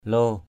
/lo:/ (p.) lắm, quá = très, beaucoup. very much, too, so. ralo lo r_l% _l% nhiều lắm = très nombreux. very much. praong lo _p” _l% to lắm = très...